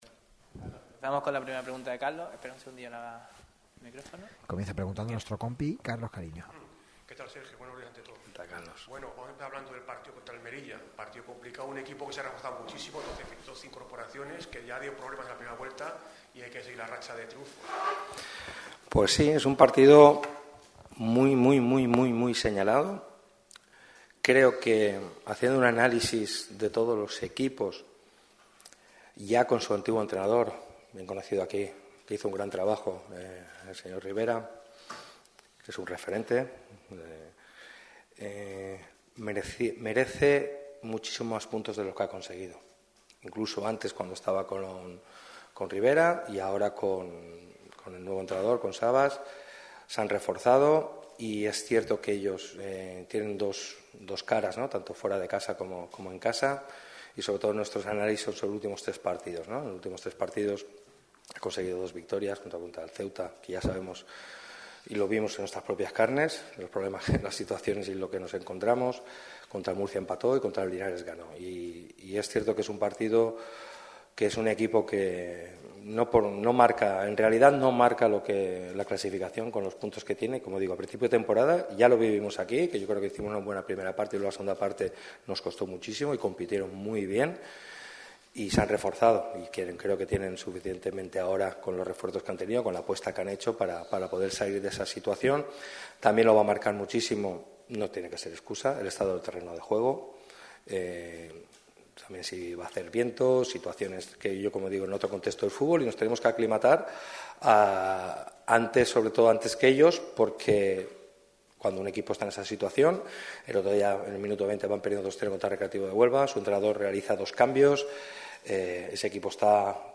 ha comparecido en la sala de prensa de La Rosaleda con motivo de la previa del encuentro del próximo domingo frente a la UD Melilla.